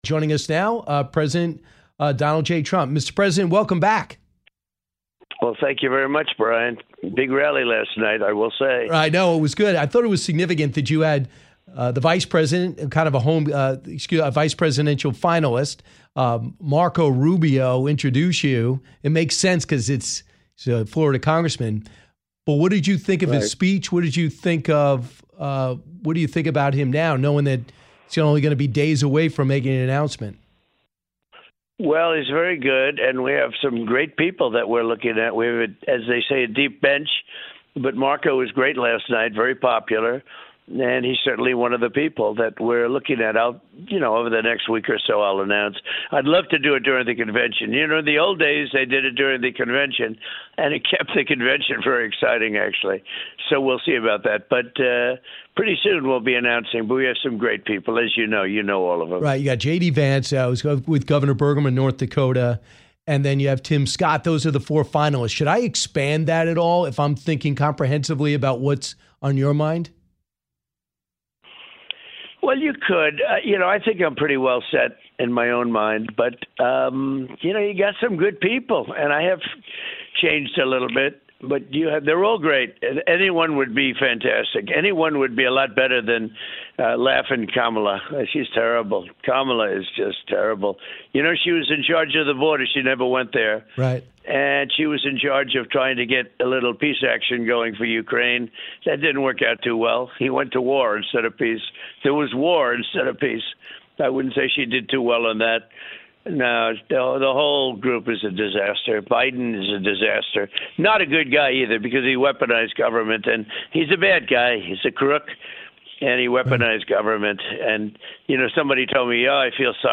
Donald Trump on the Brian Kilmeade Show - Full Interview